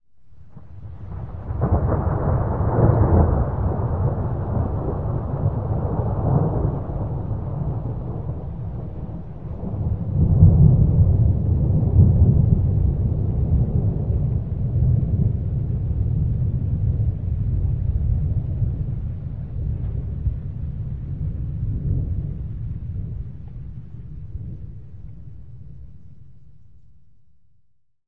thunderfar_8.ogg